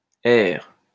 wymowa:
(1) (2) IPA[ɛʁ] ?/i